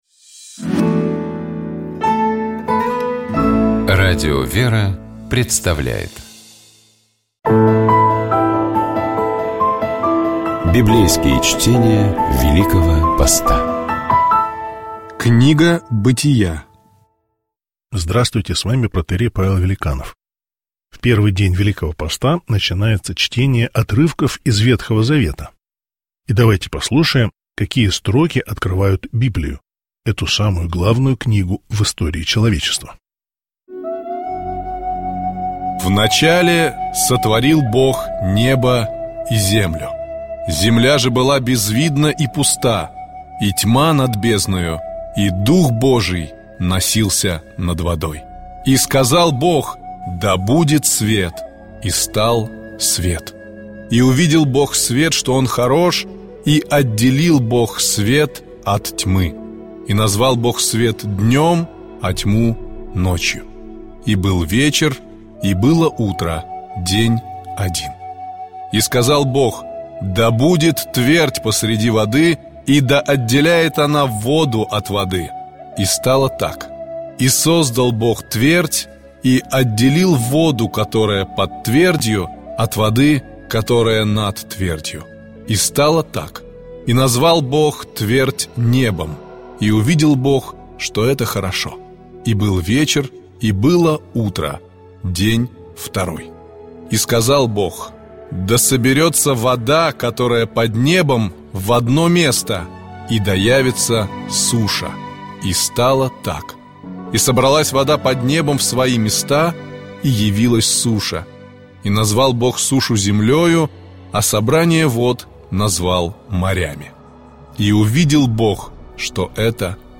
Читает и комментирует протоиерей